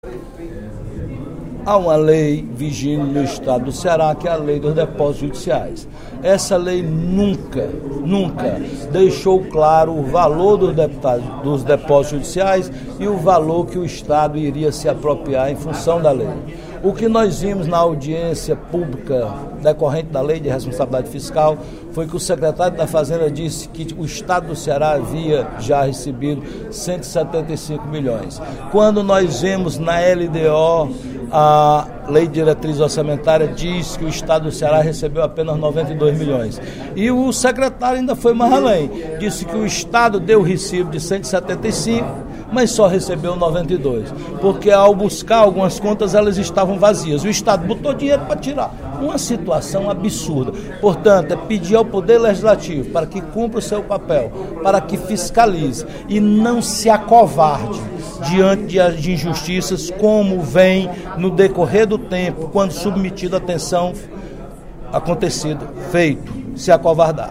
O deputado Roberto Mesquita (PSD) fez uma avaliação nesta terça-feira (31/05), no primeiro expediente da sessão plenária, das informações sobre o cumprimento das metas fiscais do 1° quadrimestre de 2016, apresentadas ontem pelo secretário da Fazenda do Estado (Sefaz), Mauro Benevides, em audiência na Assembleia Legislativa. Ele questionou os dados sobre a utilização de recursos dos depósitos judiciais.